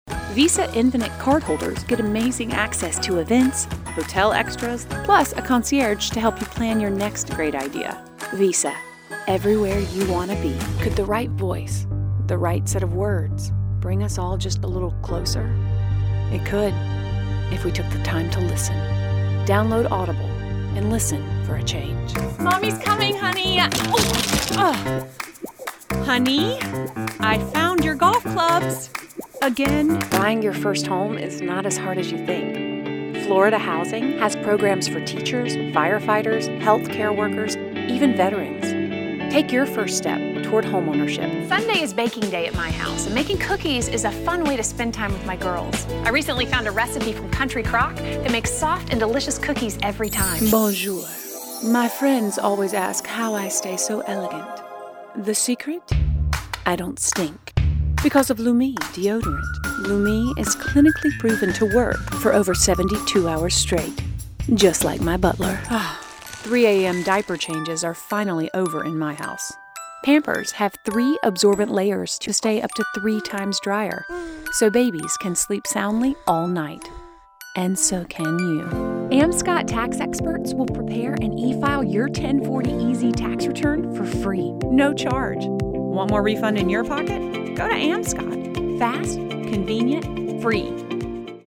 Female Voice Over, Dan Wachs Talent Agency.
Warm, Personal, Conversational, Motivating
Commercial